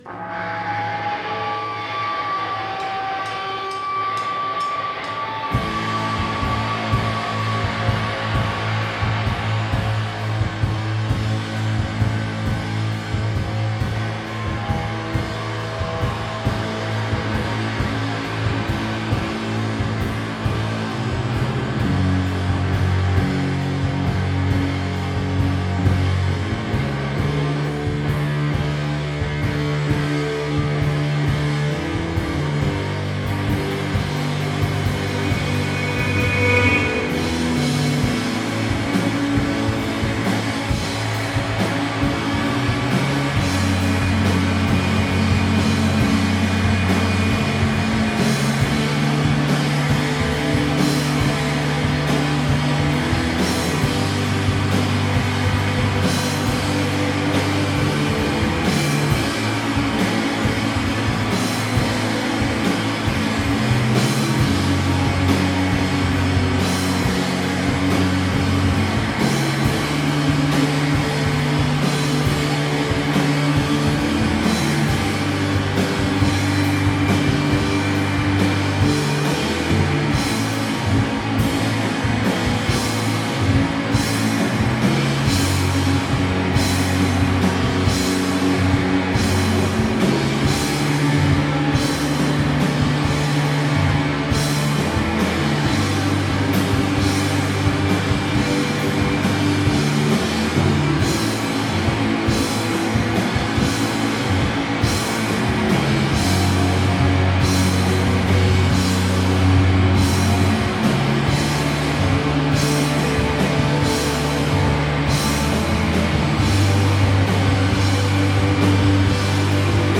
2006-10-15 Nectar Lounge – Seattle, WA